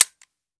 weap_decho_fire_first_plr_01.ogg